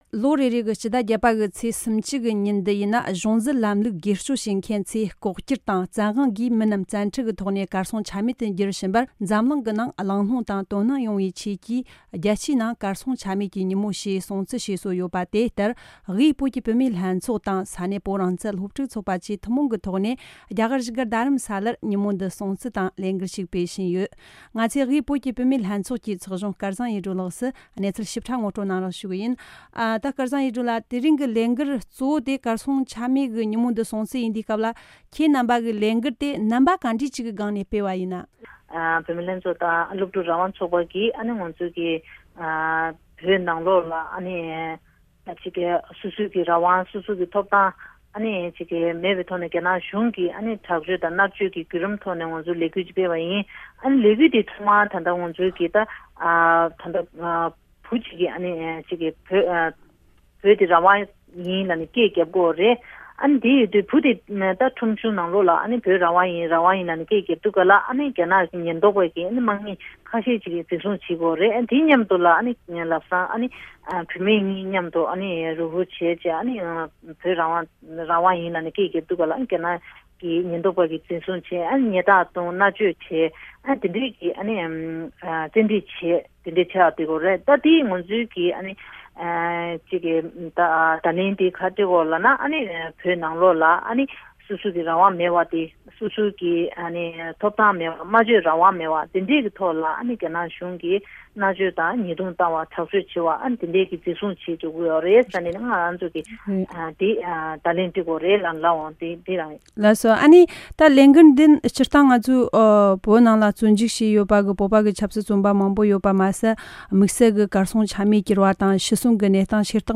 གཏམ་བརྗོད་འཁྲབ་སྟོན་གྱིས་དུས་དྲན་སྲུང་བརྩི།
བཞུགས་སྒར་རྡ་རམ་ས་ལའི་སྲང་ལམ་གྱི་མི་ཚོགས་འདུ་སར་དབུས་བོད་ཀྱི་བུད་མེད་ལྷན་ཚོགས་དང་བོད་རང་བཙན་སློབ་ཕྲུག་ཚོགས་པ་གཉིས་མཉམ་འབྲེལ་གྱིས་བོད་ནང་གི་གཞིས་ལུས་བོད་པ་ཚོའི་གནས་སྟངས་དང་། ལྷག་པར་དུ་ཞི་བའི་ངོ་རྒོལ་སོགས་ཀྱི་ལས་འགུལ་སྐབས་སུ་རྒྱ་ནག་གཞུང་གིས་འཛིན་བཟུང་བཙན་ཁྲིད་བྱེད་བཞིན་པ་རྣམས་གཏམ་བརྗོད་ལམ་ནས་འཁྲབ་སྟོན་བྱས་ཏེ་རྒྱལ་སྤྱིའི་གར་སོང་ཆ་མེད་ཀྱི་ཉིན་མོར་སྲུང་བརྩི་ཞུས་འདུག་པའི་སྐོར།